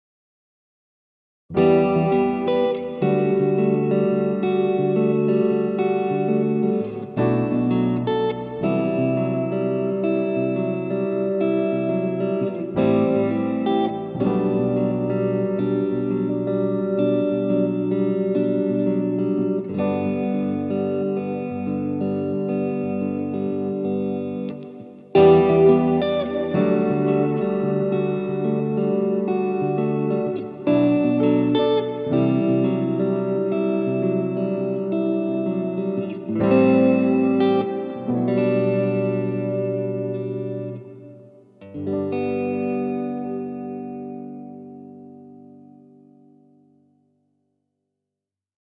• Baggrundsmusik
• Instrumentalt
• Jazz
• Pop
Baggrundsjazz kan ønskes:) Guitar og klaver
Sofapop tilbyder hyggelig baggrundsmusik, et funky indslag eller fortolkede velkendte klassikkere til din fest, reception, bryllup, konfirmation eller lignende